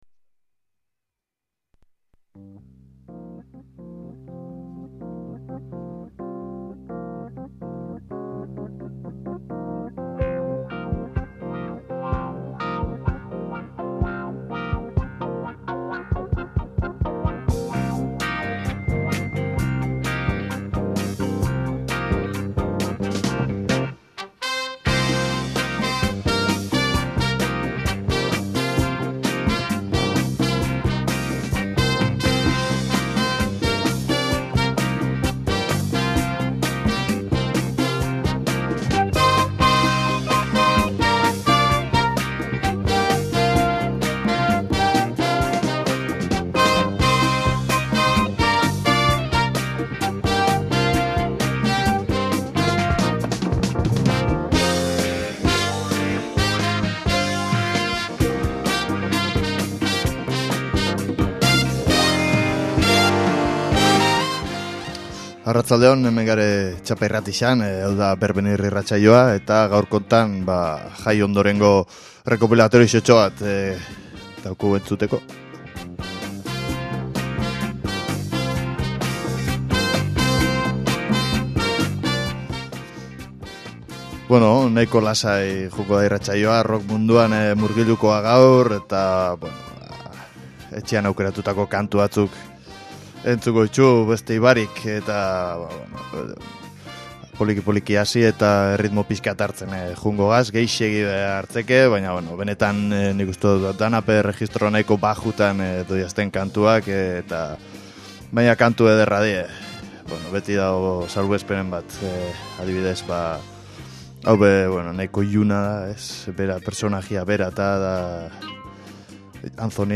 Zaharrenak, berrienak, azkarrak, geldoak, alaiak, tristeak, ezagunak edo ezezagunak. Klasikotik, elektronikaraino estilo eta doinu ugari entzun ahalko dituzu BERBENIR musika irratsaioan.Astero ordubete.